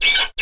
Menu Grow Taller Sound Effect